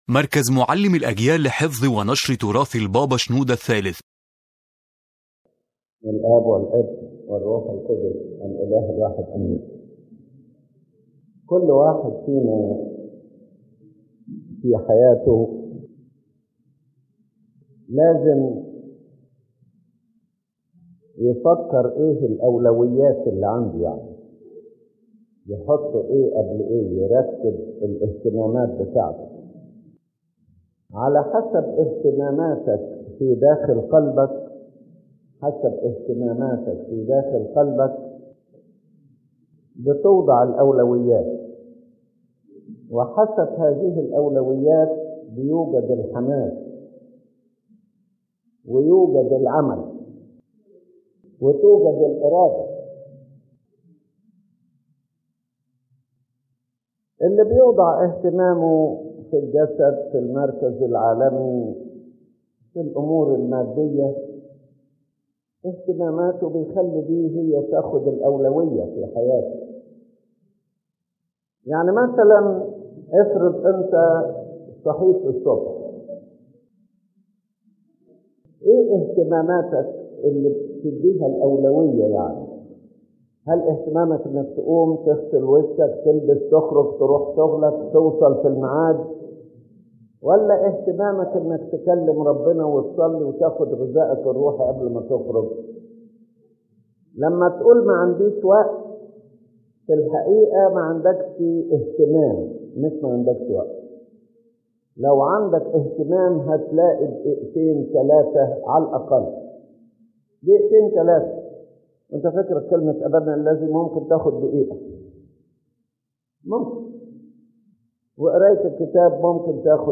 This lecture focuses on the importance of priorities and inner concern in a person’s spiritual life, explaining that whatever occupies the heart determines the direction of life, guides the mind, moves the will, and shapes behavior.